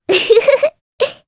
Rephy-Vox_Happy2_jp.wav